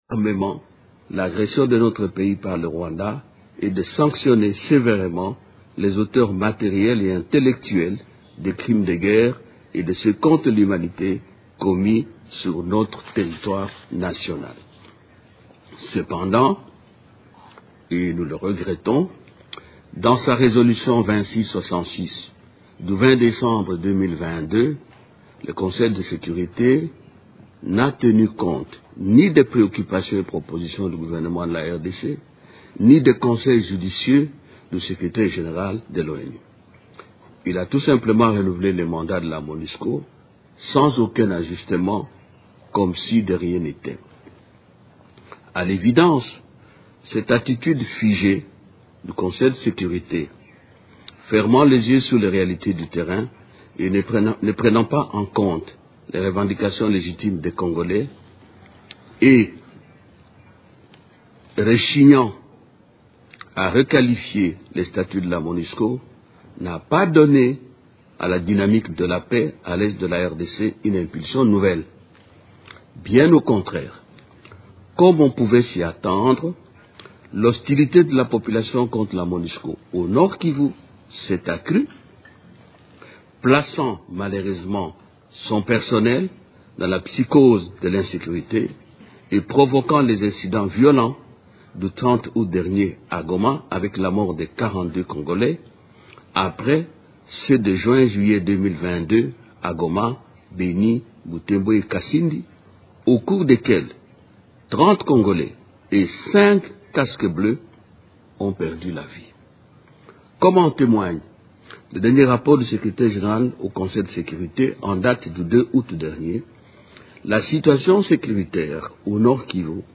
Lors de son intervention devant le Conseil de sécurité de l’ONU, jeudi 28 septembre à New York, le ministre des Affaires étrangères, Christophe Lutundula a accusé le Rwanda de renforcer ses troupes dans l’Est de la RDC.